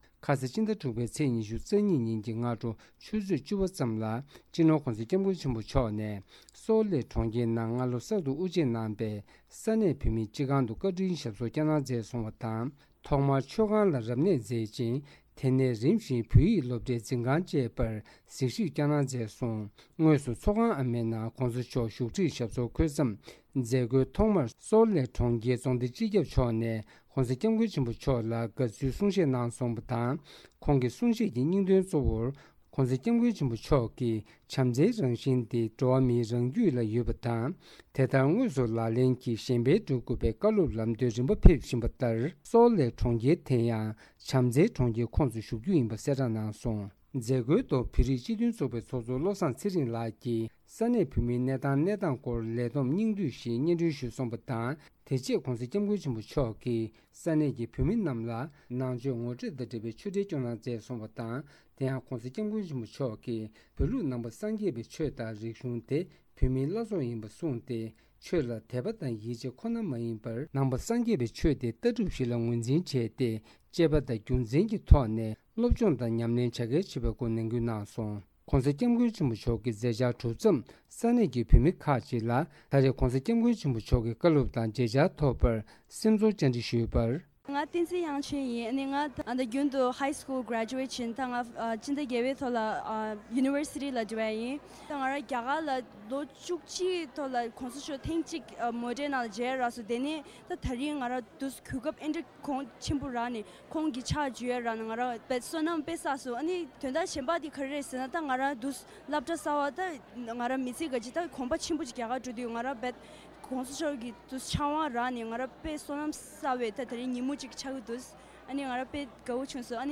༧གོང་ས་མཆོག་ནས་ས་གནས་བོད་མི་རྣམས་ལ་ཆོས་འབྲེལ་མཛད་གནང་བ། སྒྲ་ལྡན་གསར་འགྱུར།